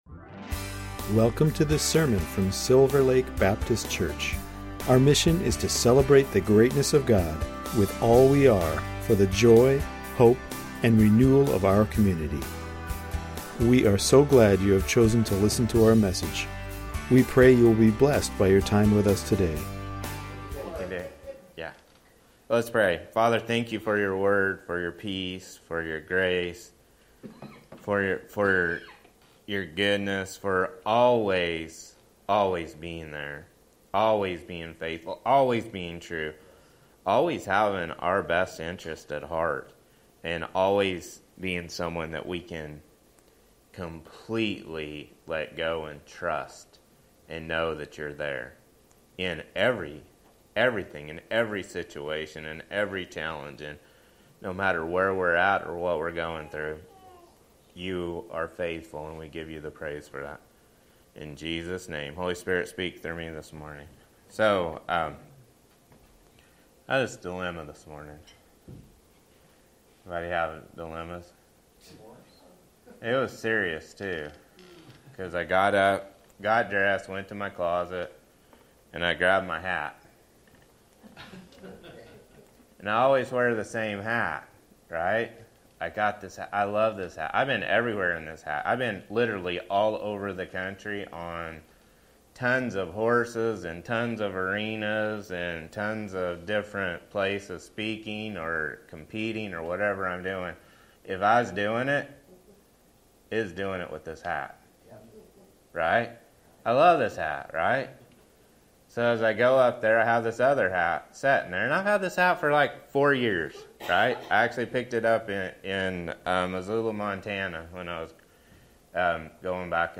Recent sermons from Silver Lake Baptist Church, Everett, WA